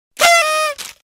Party Horn